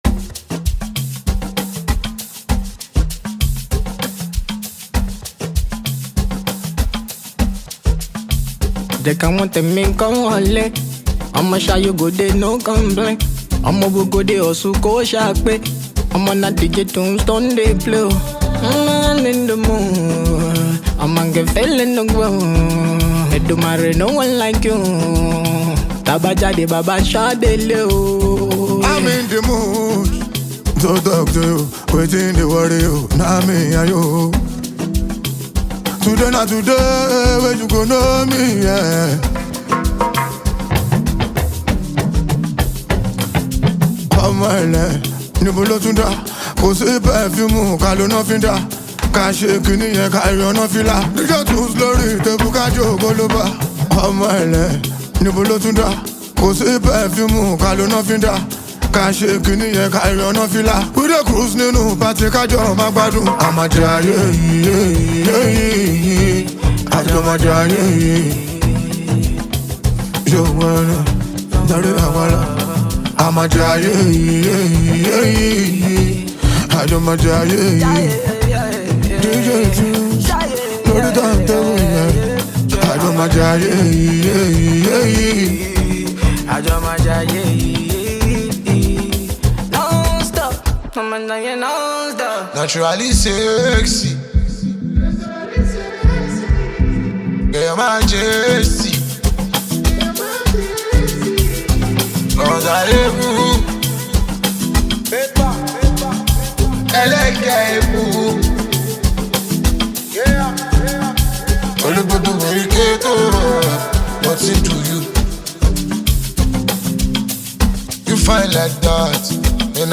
Afro Fuji Pop, Fuji